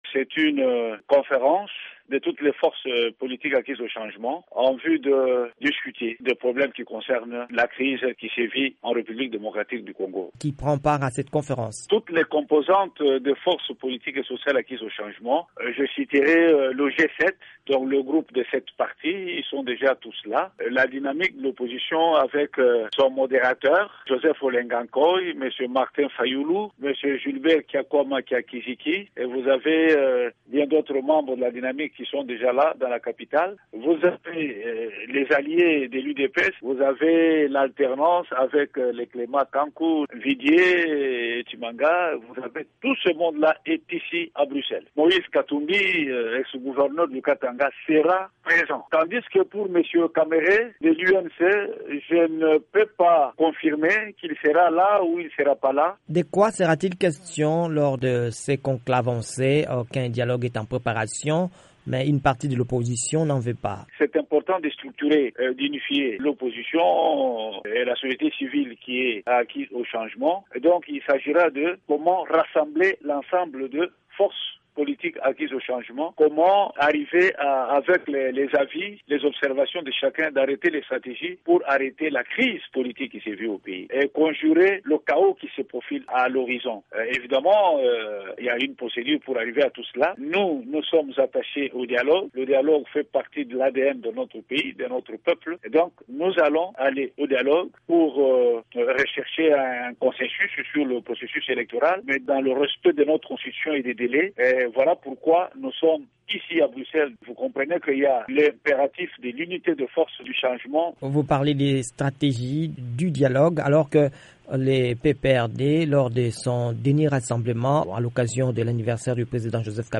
dans une interview avec VOA Afrique